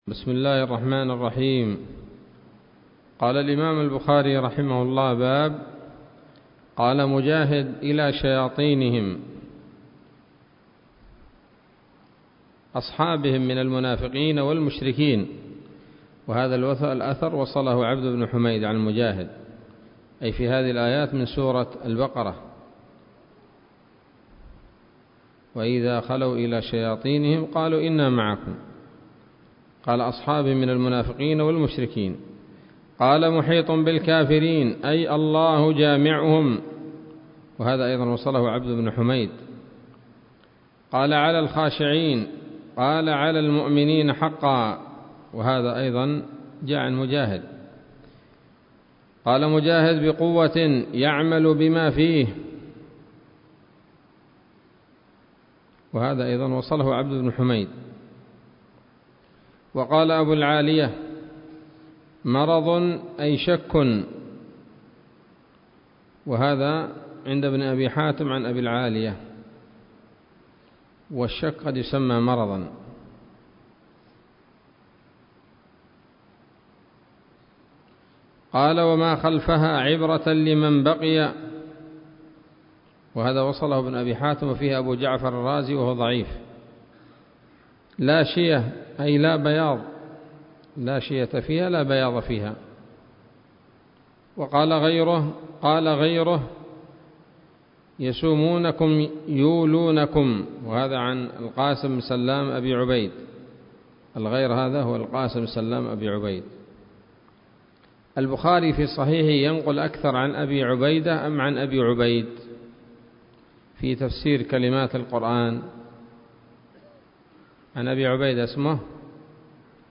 الدرس الرابع من كتاب التفسير من صحيح الإمام البخاري